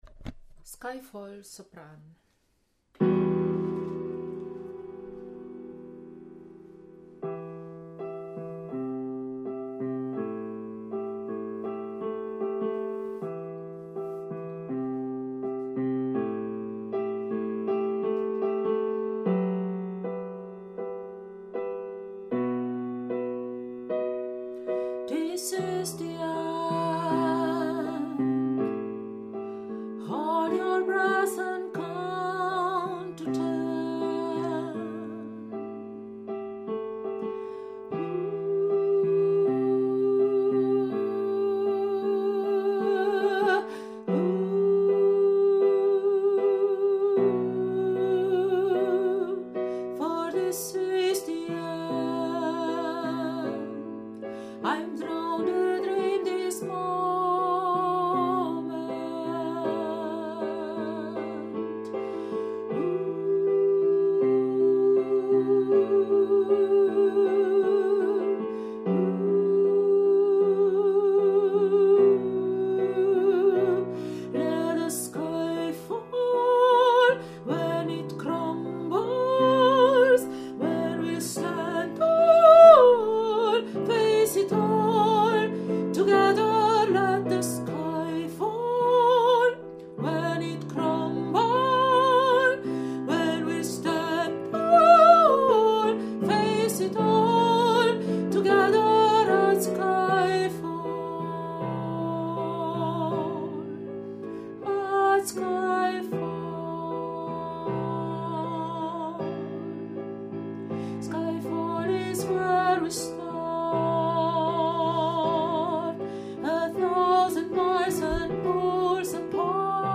Skyfall Sopran
Skayfall-Sopran.mp3